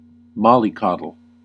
Ääntäminen
Synonyymit spoil coddle Ääntäminen US : IPA : /ˈmɑl.iˌkɑd.əl/ UK : IPA : /ˈmɒl.ɪˌkɒd.əl/ Haettu sana löytyi näillä lähdekielillä: englanti Määritelmät Substantiivit (now rare) A person, especially a man or a boy, who is pampered and overprotected .